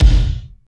32.08 KICK.wav